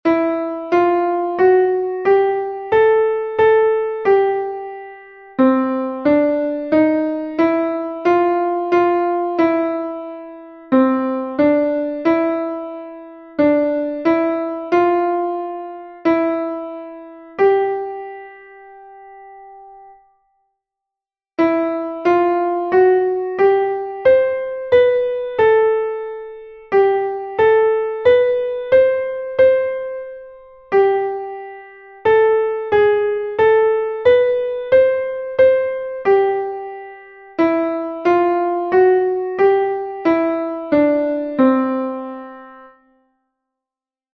Exercise 1: 4/4 time signature.